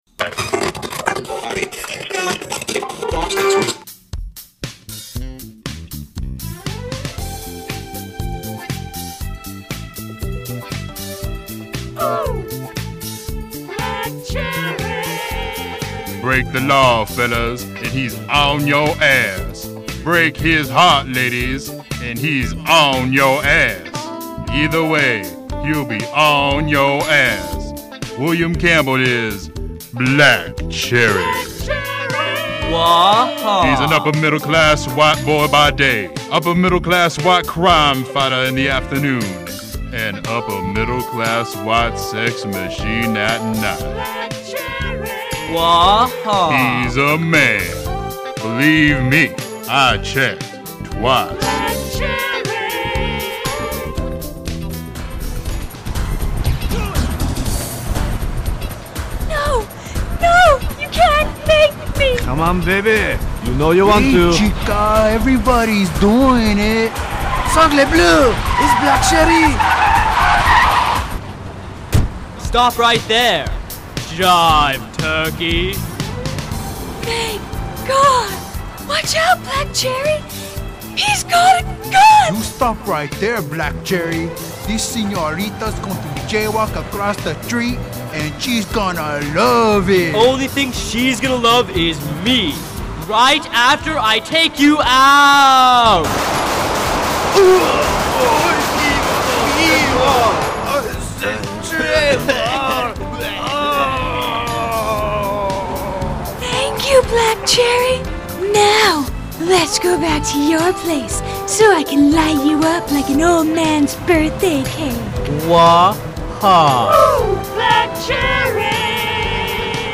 using Audacity Freeware I had to scale back on the sound quality to get it on here
This is an audio preshow for my improv Group's live show 11/14/05.